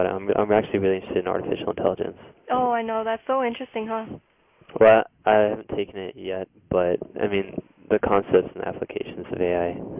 Functions of Bookended Narrow-Pitch-Range Regions